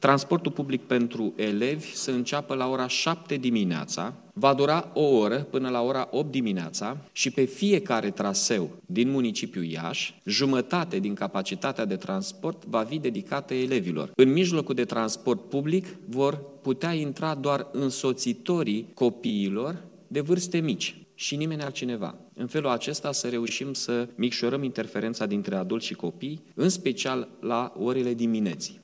Între orele 7 și 8 dimineața, jumătate dintre mijloacele de transport în comun vor fi folosite exclusiv de elevi, spune primarul Mihai Chirica. La întoarcerea elevilor spre case nu va fi însă la fel: